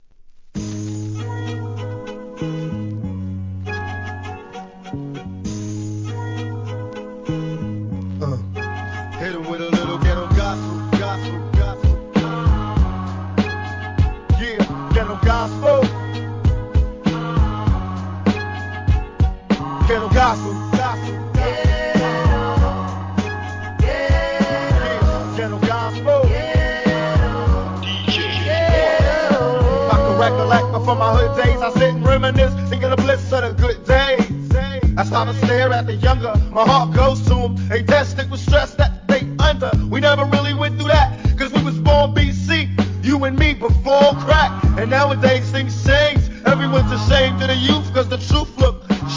HIP HOP/R&B
REMIX BLEND物!!